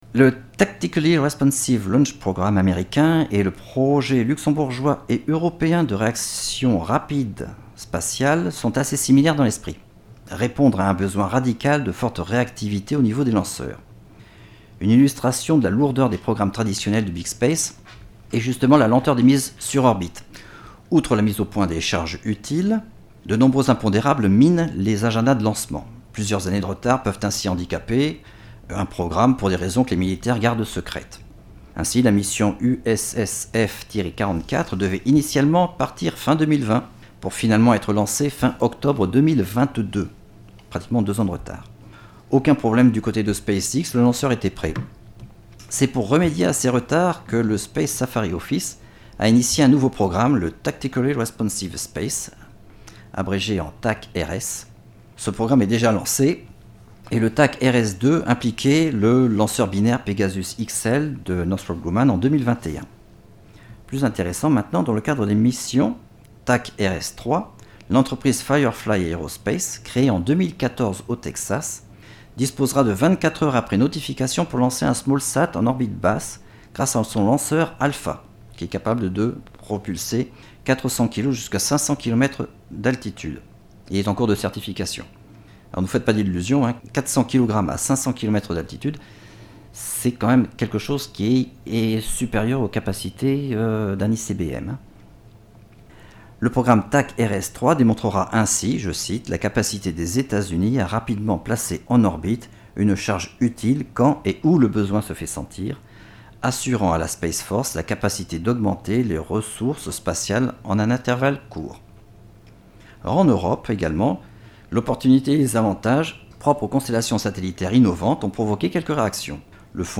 Une conférence en 8 épisodes audio :
La conférence vous est proposée en replay sous la forme d'une série audio en 8 épisodes.
Audio conférence CDEM IRSEM constellations satellitaires_7.mp3